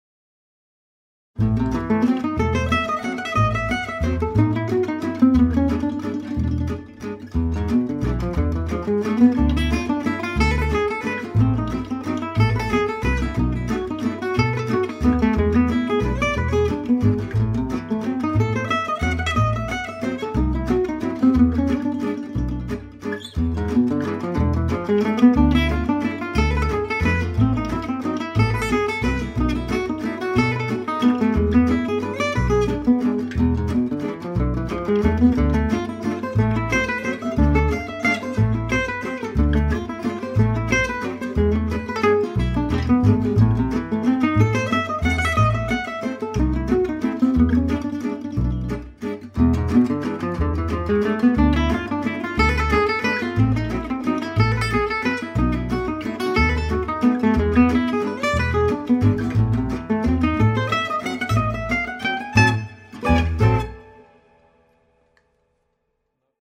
For example, he came up with this for these sort of large jumps, it's in the B section here